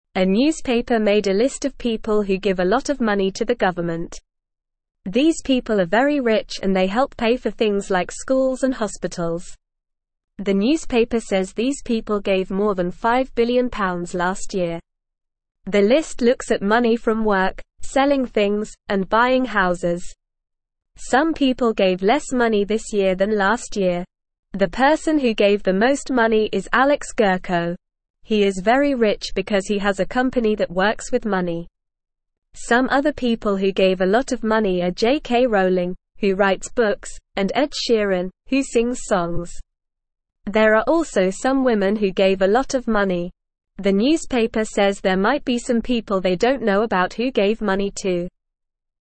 Normal
English-Newsroom-Beginner-NORMAL-Reading-Rich-People-Give-Lots-of-Money-to-Help.mp3